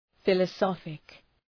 Προφορά
{,fılə’sɒfık}